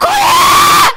Worms speechbanks